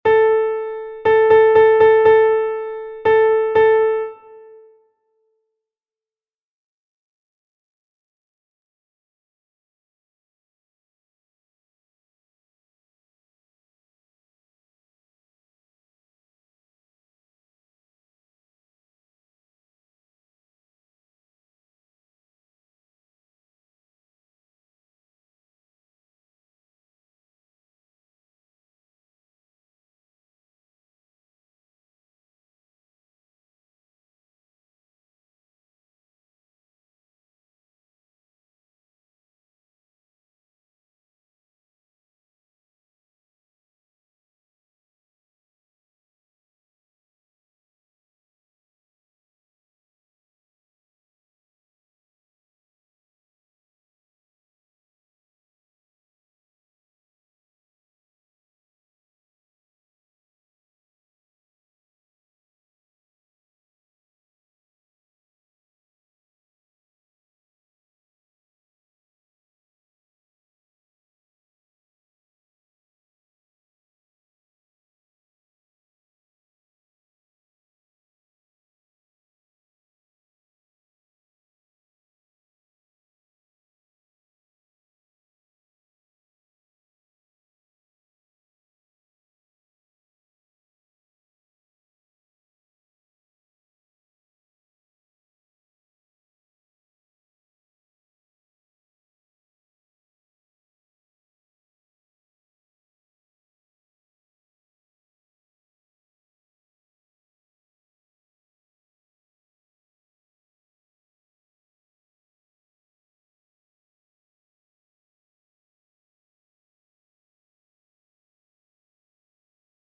DICTADO RÍTMICO 4 TIEMPOS
DICTADO RÍTMICO EN COMPÁS DE 4 POR 4